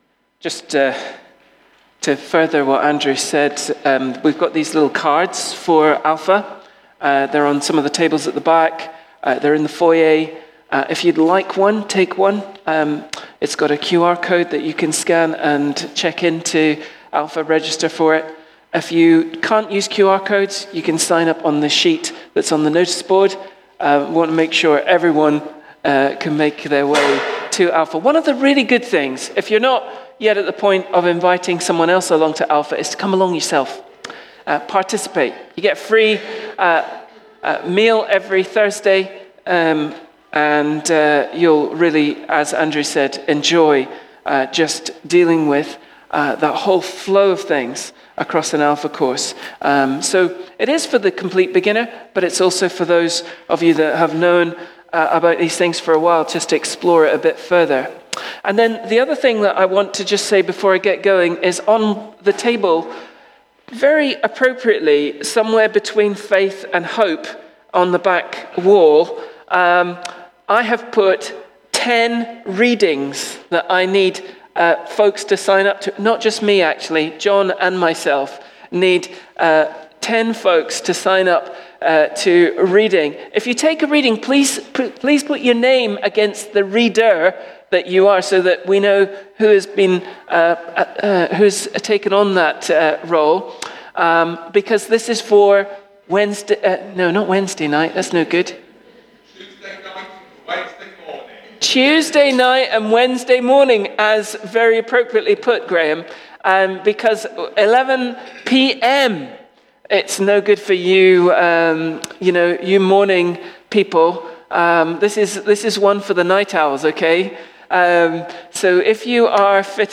Bridge of Don Baptist Church Sermons